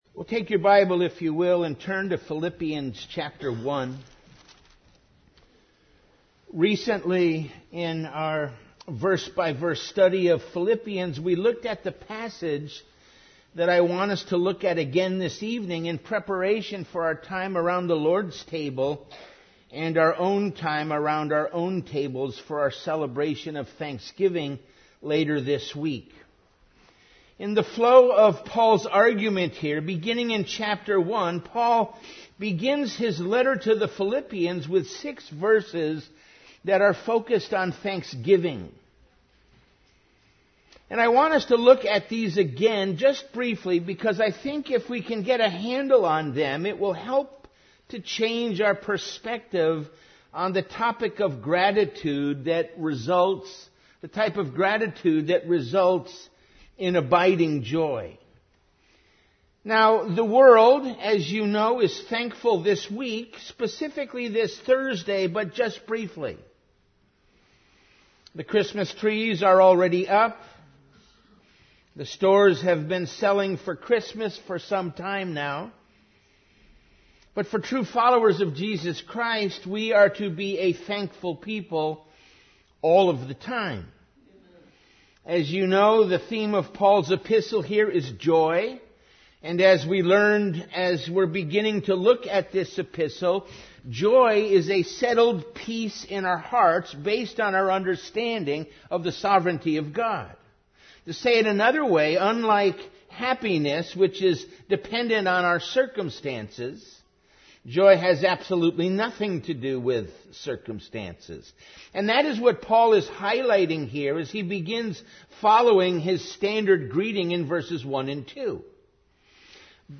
Series: Evening Worship